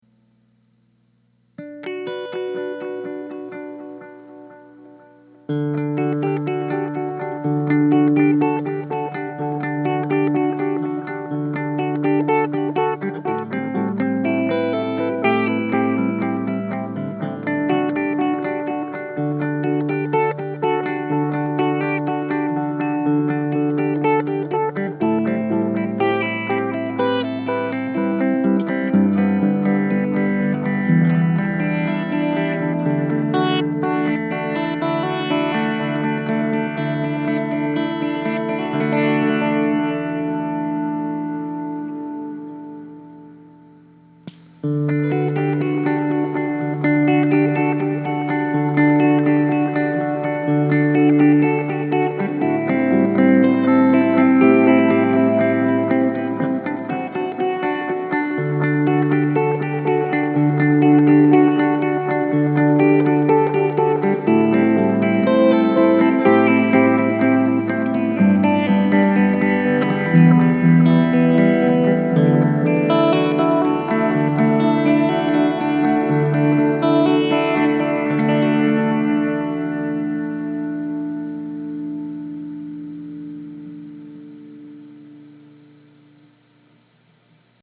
The clips below were all recorded using my 1958 Fender Champ output to a Jensen Jet Falcon 1 X 12.
Squier CV Tele Middle Pickup. Reverb: 10am, Mix 10am
MXR Carbon Copy with long delay time, Mix at about 10am
sf_clean_delay_tele.mp3